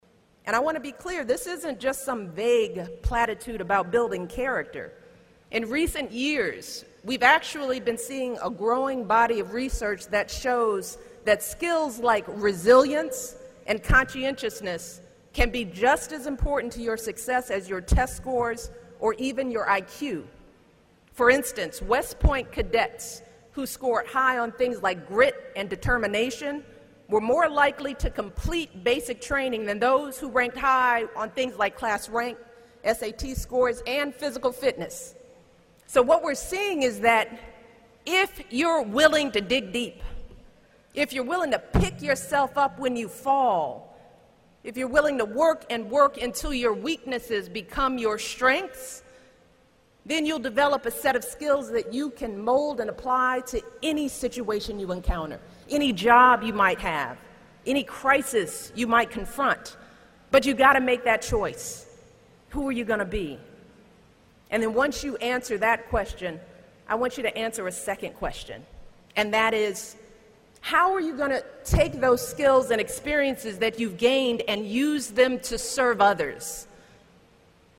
公众人物毕业演讲第269期:米歇尔2013东肯塔基大学10 听力文件下载—在线英语听力室